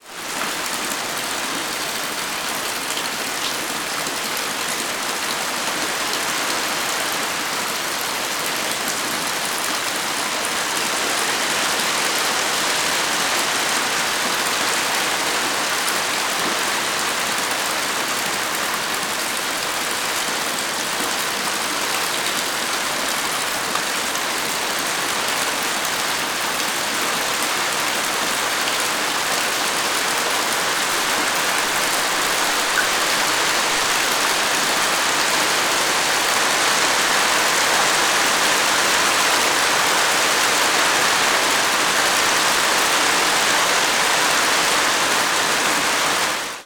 zapsplat_nature_rain_med_getting_heavier_heavy_drips_into_deep_water_close_by_17739